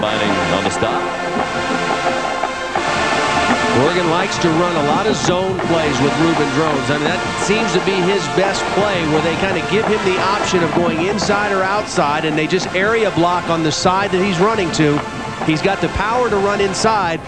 We have a clip of the entire band playing the
Les Mis Fanfare.  We wail on this one, check it out -  Sun Bowl 1999.